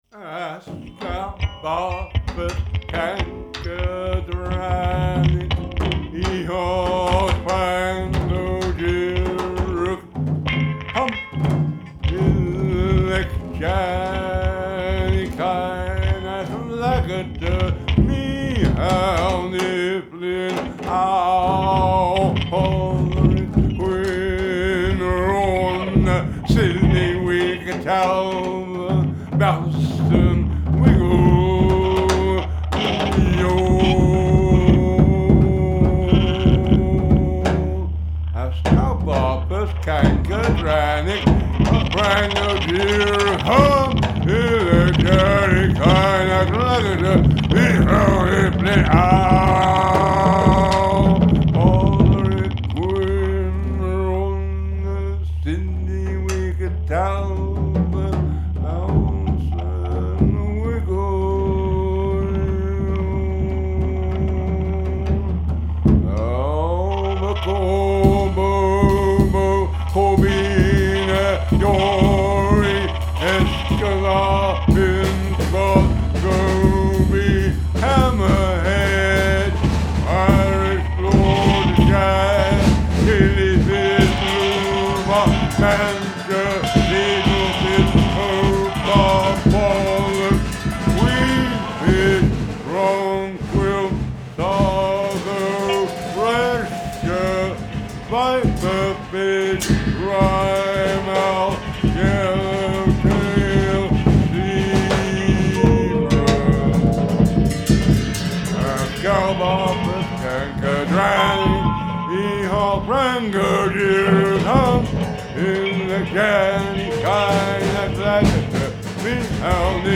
voice
drums
guitar
Recorded at Dave Hunt Studio, London, 16th October, 2000.